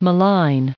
added pronounciation and merriam webster audio
895_malign.ogg